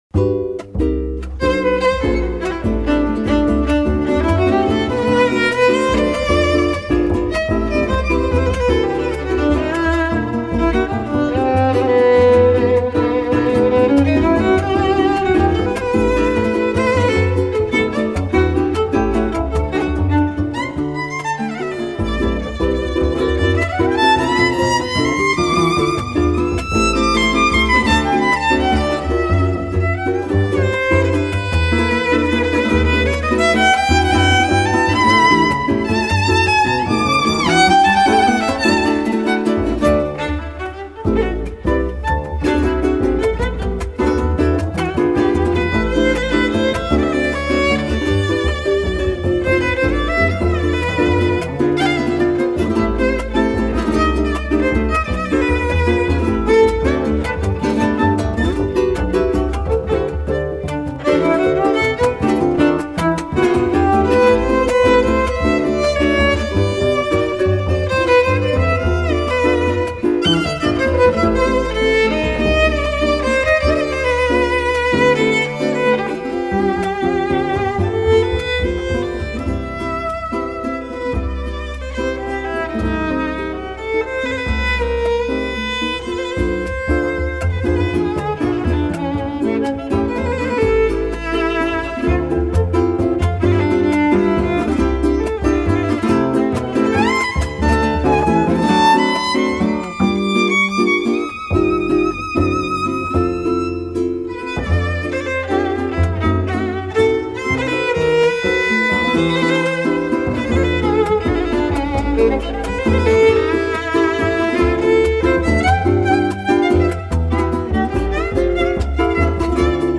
Pars Today- La música de América Latina.